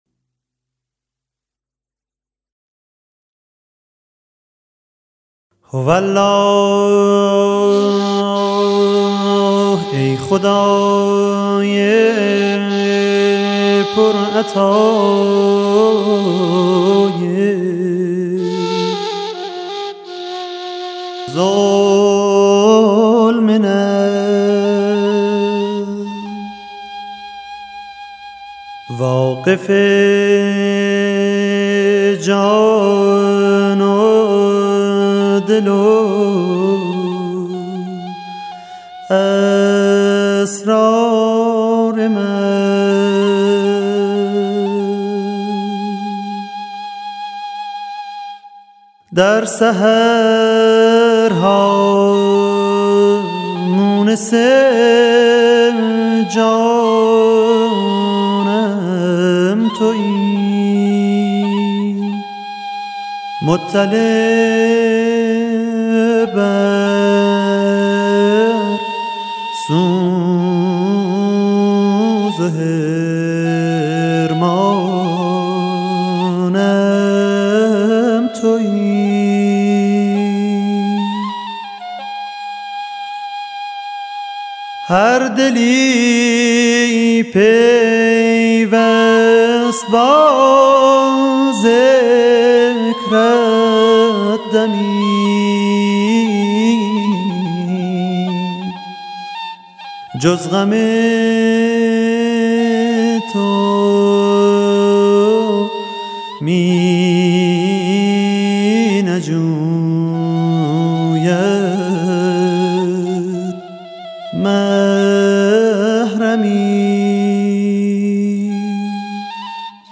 مناجات حضرت عبدالبهاء ( مثنوی افشاری )
قند پارسی لحن فارسی